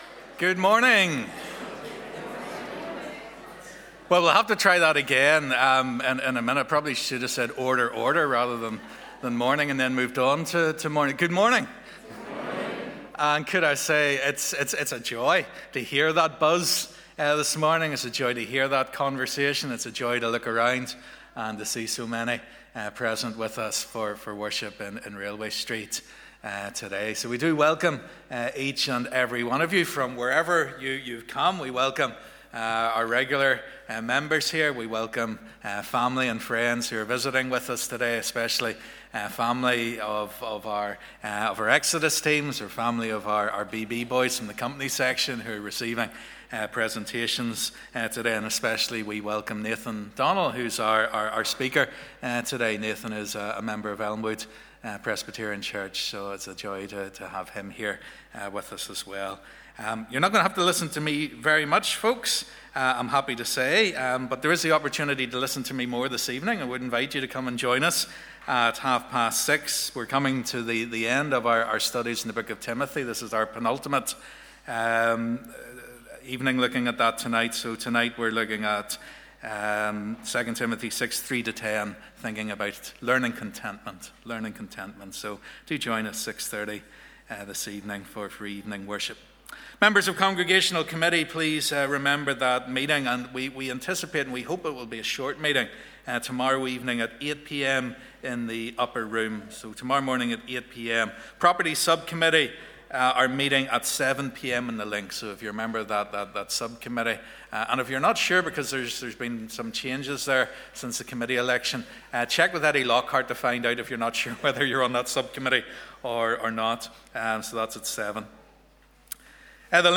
This morning we say thank you to God for all His goodness to us among our children and young people this year, and look forward to all the summer teams and camps. We will also be handing out gifts to the children in our church family and presenting Boy's Brigade Presidents and Queens badges to those who achieved them this year.